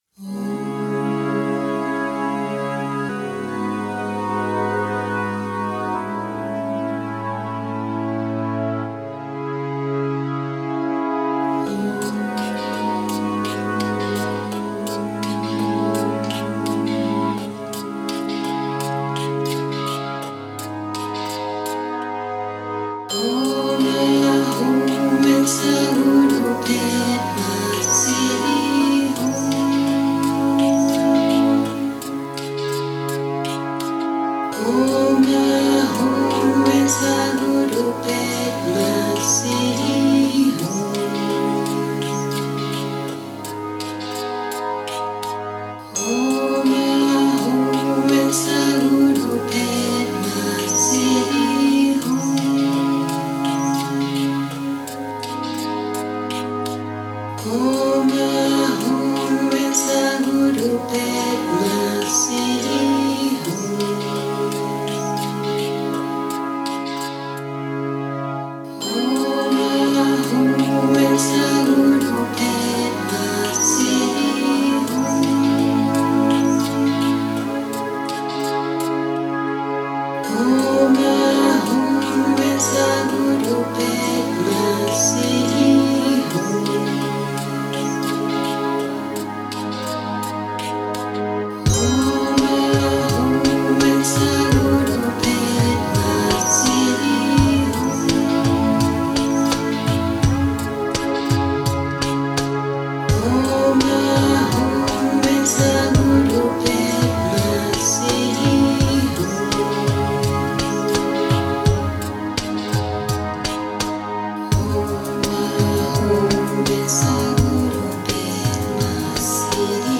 Om Ah Hung Benza Guru Pema Siddhi Hung (Dialectic variation in Tibetan pronunciation.)